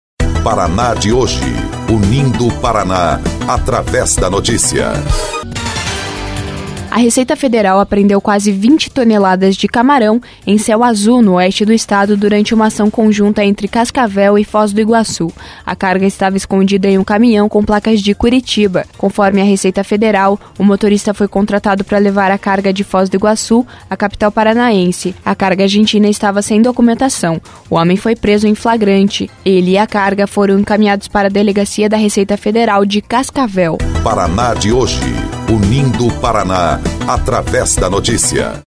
25.07 – BOLETIM – Receita Federal apreende mais de 15 toneladas de camarão em Céu Azul, no oeste